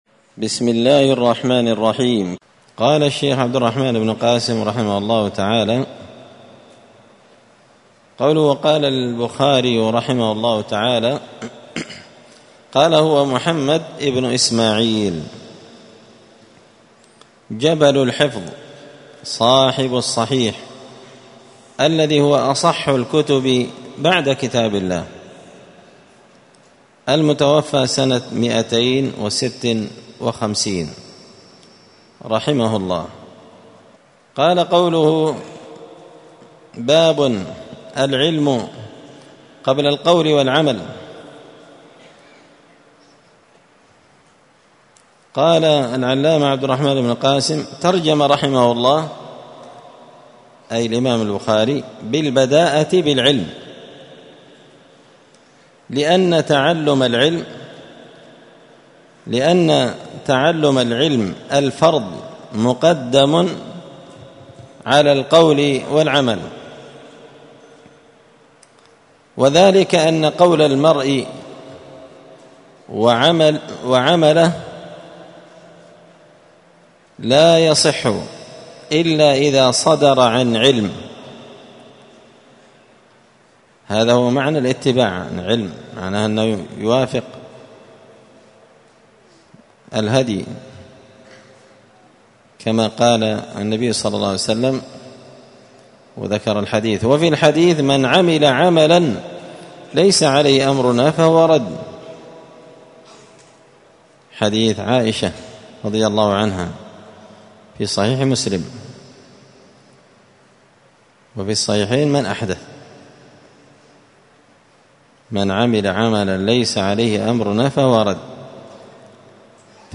الأربعاء 3 ربيع الثاني 1445 هــــ | الدروس، حاشية الأصول الثلاثة لابن قاسم الحنبلي، دروس التوحيد و العقيدة | شارك بتعليقك | 52 المشاهدات
مسجد الفرقان قشن_المهرة_اليمن